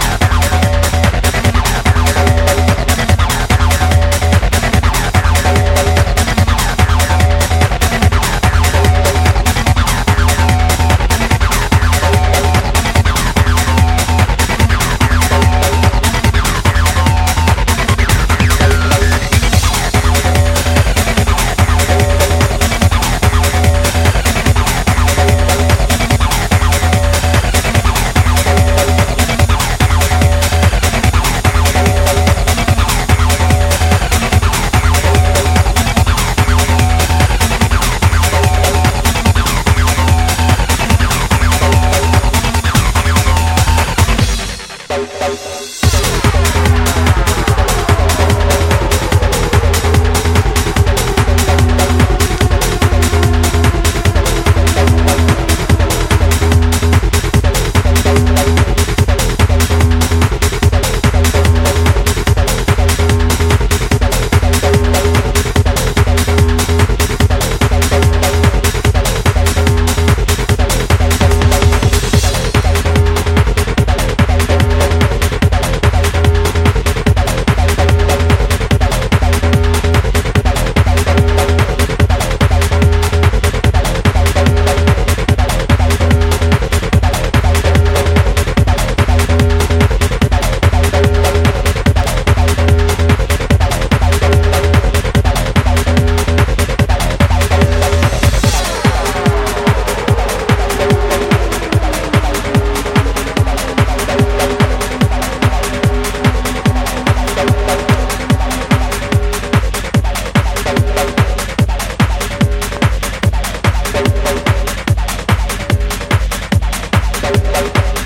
Acid Techno 12 Inch Ep